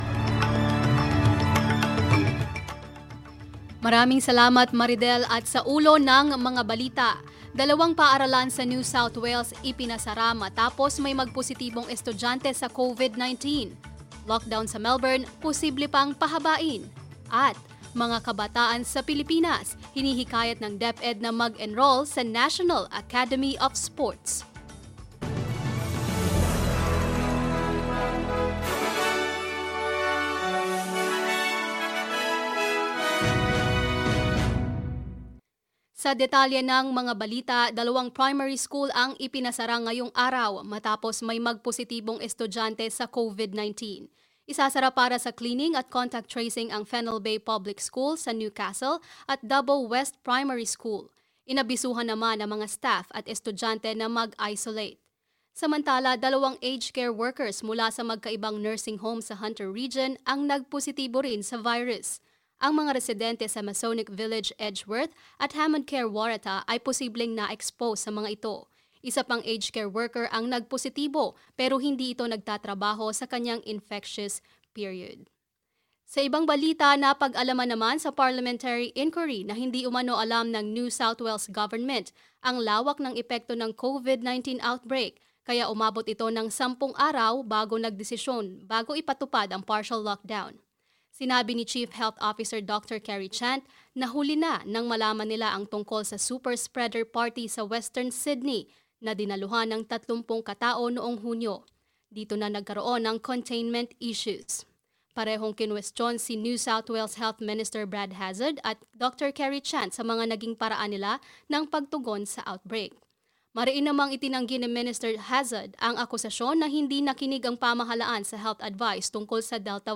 Mga balita ngayong ika-11 ng Agosto